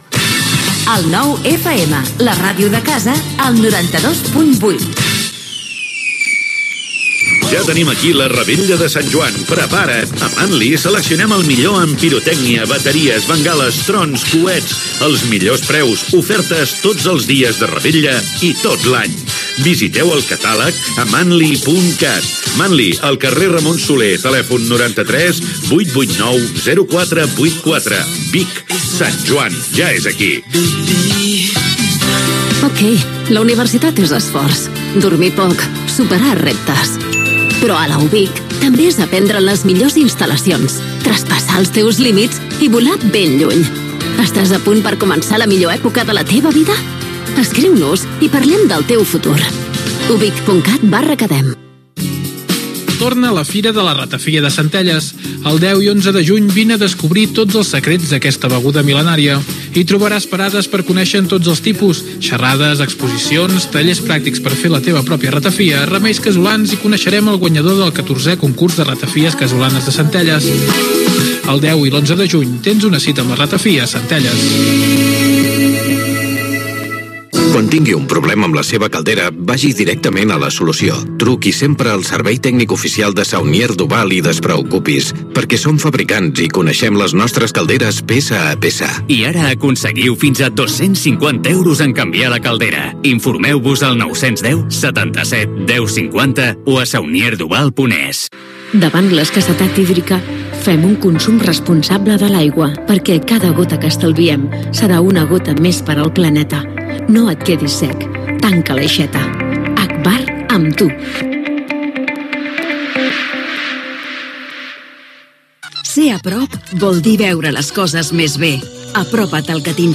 Indicatiu de l'emissora, publicitat, promoció de "Cocodril Club", telèfon de l'emissora, indicatiu del programa, "A tren d'Alba" problemes a la línia ferroviària R3, indicatiu de l'emissora, ràdios connectades, hora, valoració del resultat de les eleccions municipals a Ripoll. Gènere radiofònic Informatiu